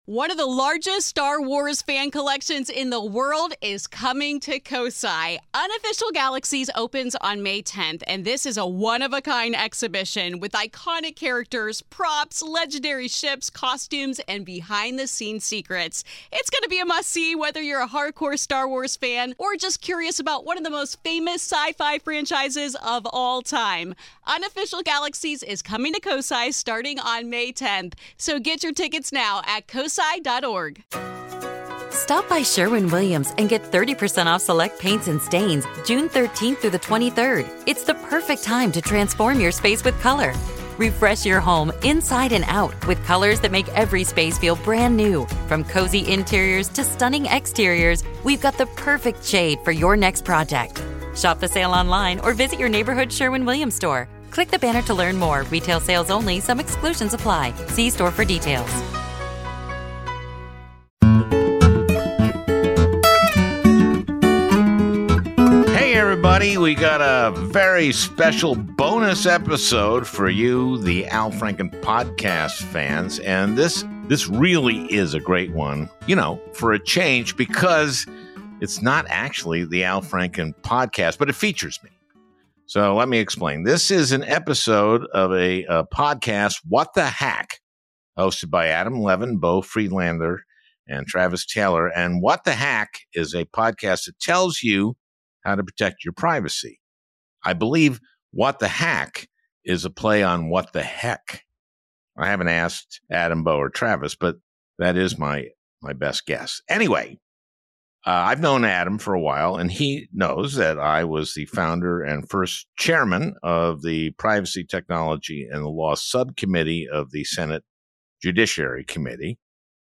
Me as Bernie, Grassley, Sherrod, Collins, and McConnell